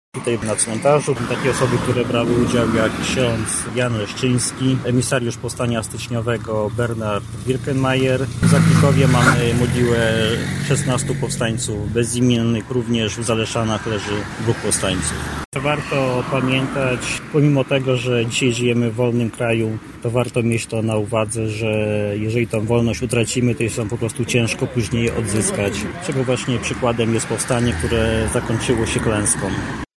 W Stalowej Woli przypomniano o rocznicy wybuchu Powstania Styczniowego. Pamięć powstańców uczczono na cmentarzu w Rozwadowie, gdzie spoczywają powstańcy.